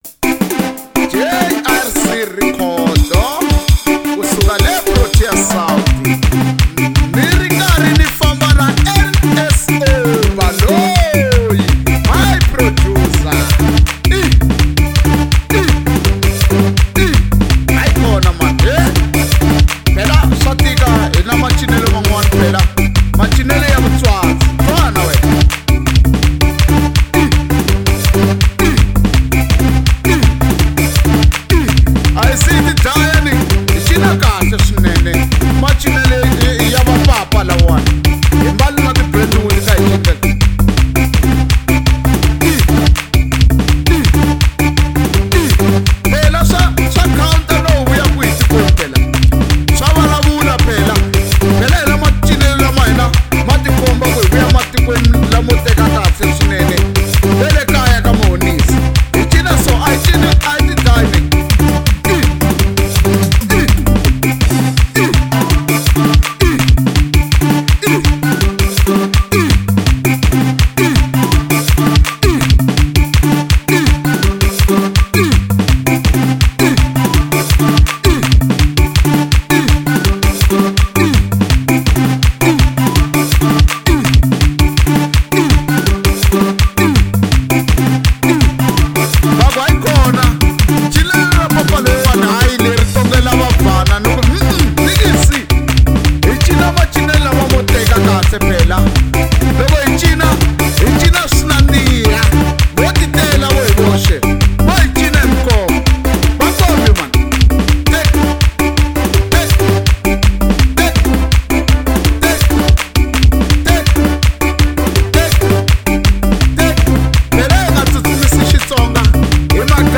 05:40 Genre : Xitsonga Size